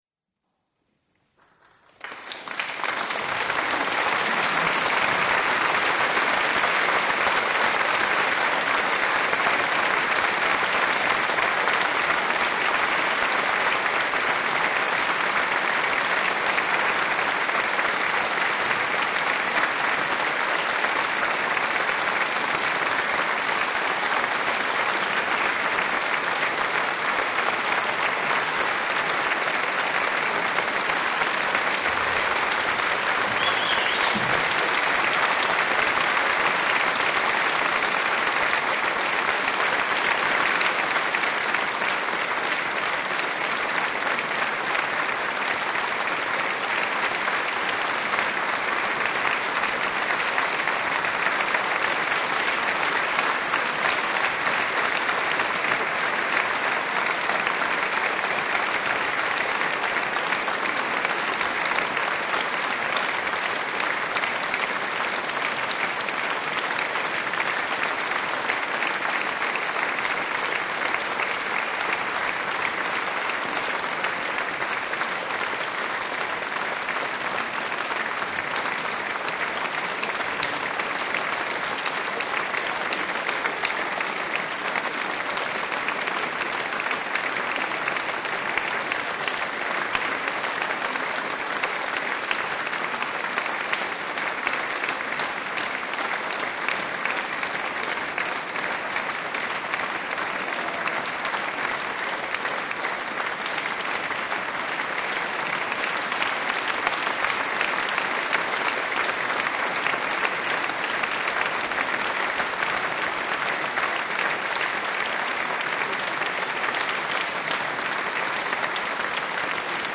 QR code – click on it and you’ll hear what you’re hearing today, but what we want you to carry with you when times are tough – the applause of your peers, supporters, and allies.
Long-Applause-MP3.mp3